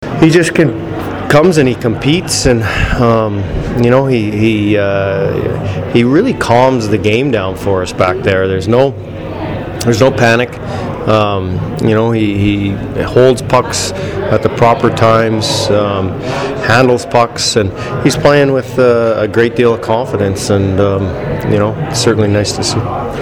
After the game I managed to catch up with Dean Evason, Filip Forsberg, and Scott Darling who gave their thoughts on the game.